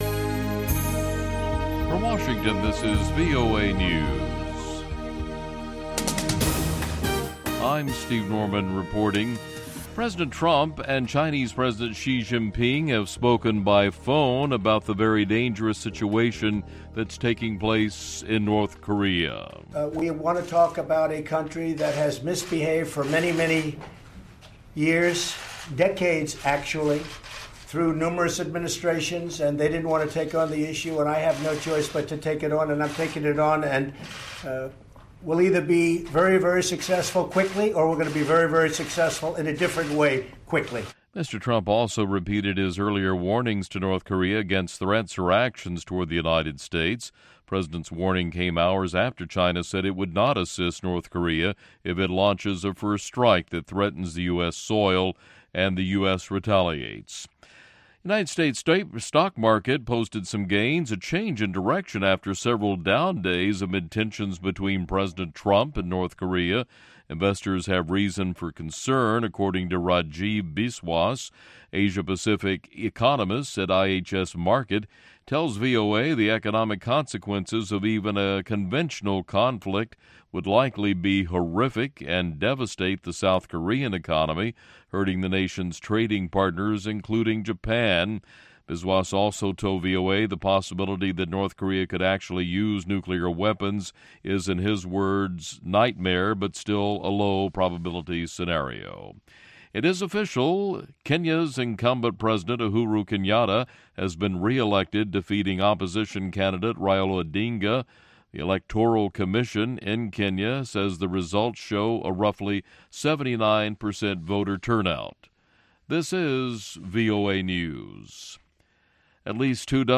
Also a few classics tossed in the mix, and wait till you hear a sample of Boko Pakata!